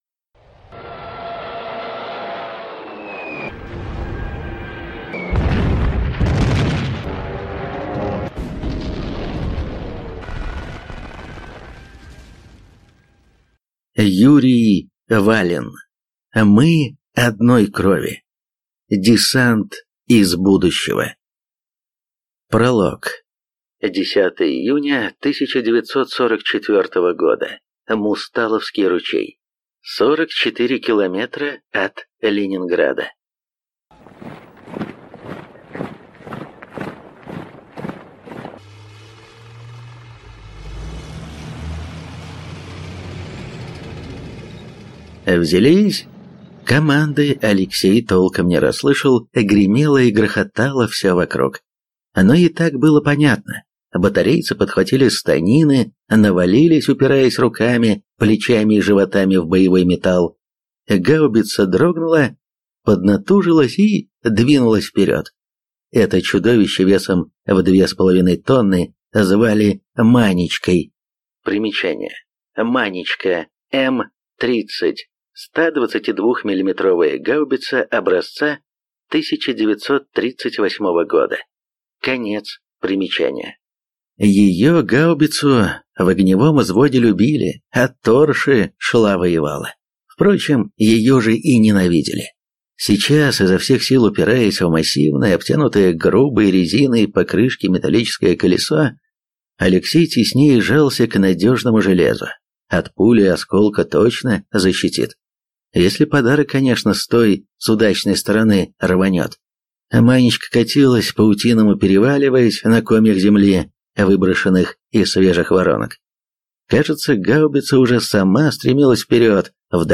Аудиокнига «Мы одной крови». Десант из будущего | Библиотека аудиокниг